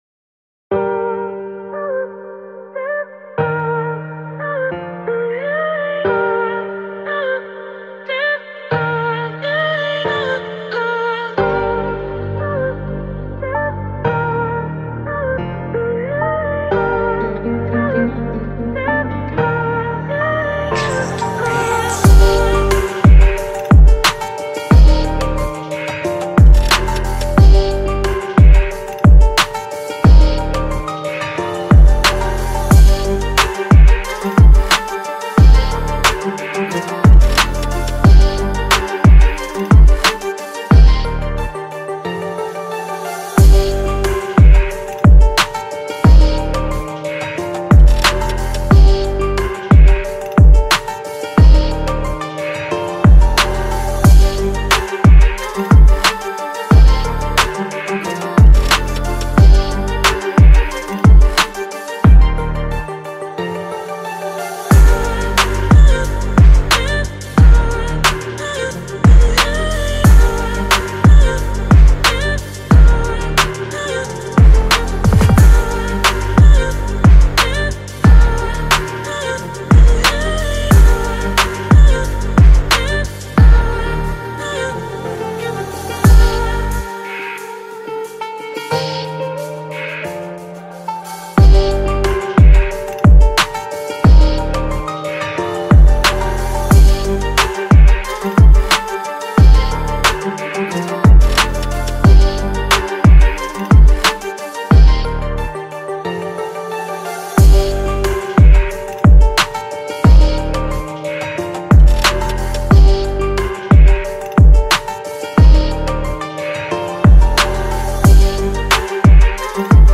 Deep Motivational Rap Beat Emotional Voice Type
Deep-Motivational-Rap-Beat-Emotional-Voice-Type.mp3